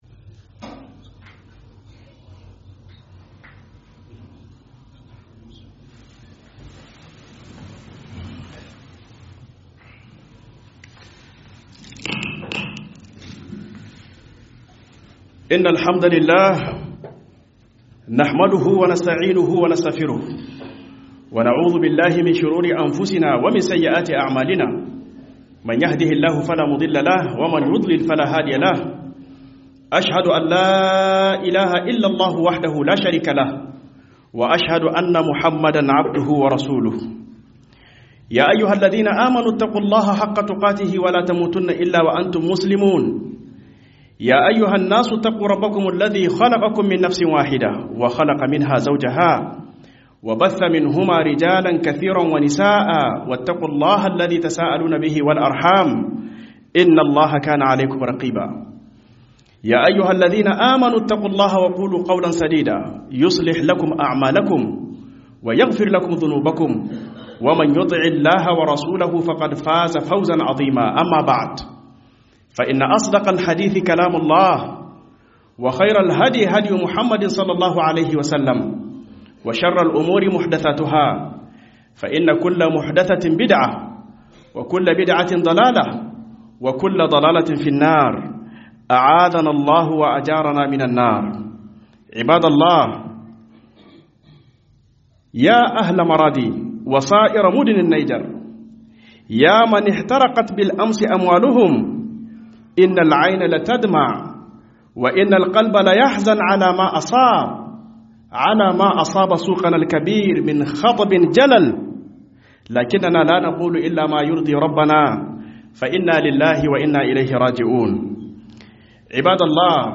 Huduba